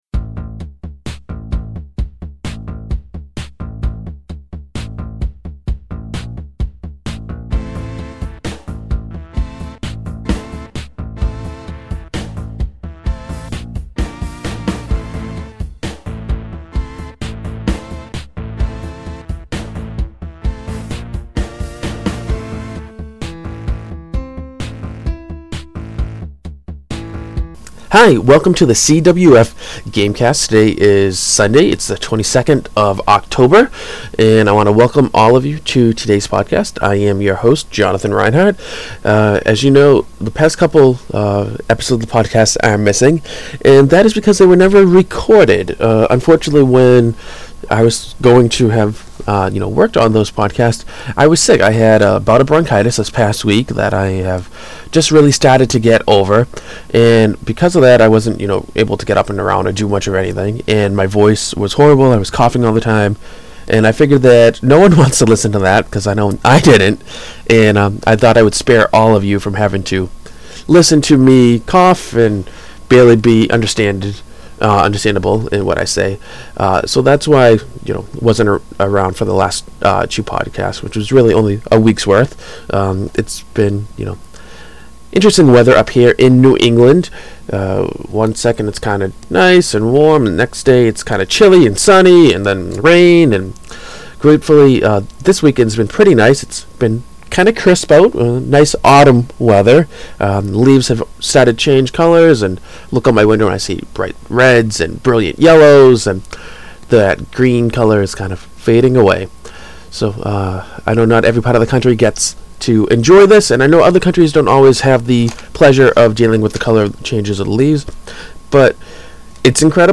We also have some tunes for you too!